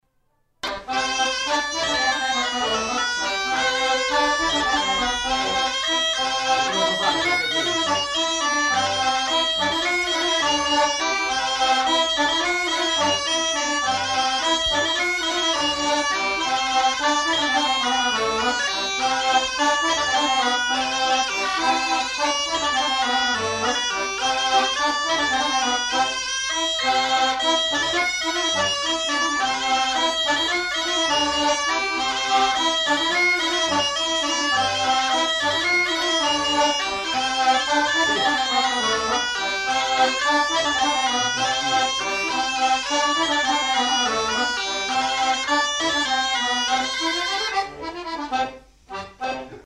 Bourrée à trois temps
Lieu : Pyrénées-Atlantiques
Genre : morceau instrumental
Instrument de musique : accordéon chromatique ; cabrette
Danse : bourrée